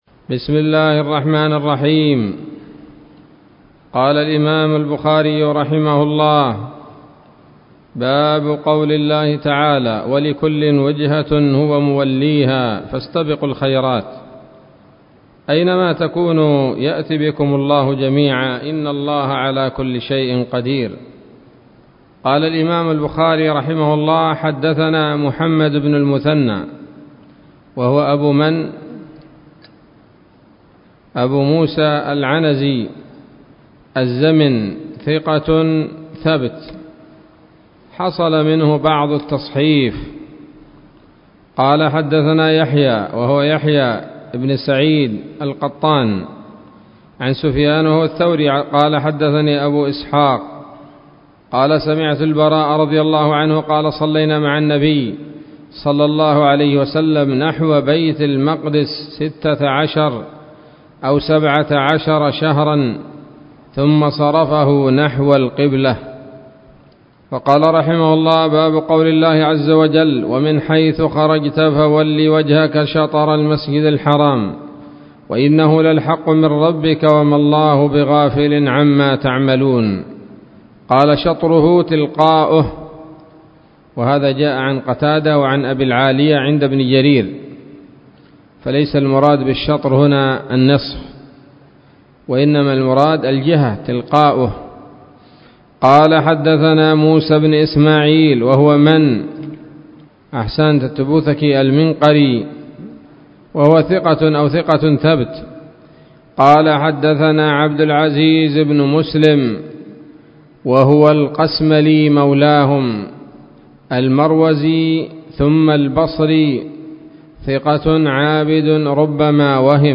الدرس السابع عشر من كتاب التفسير من صحيح الإمام البخاري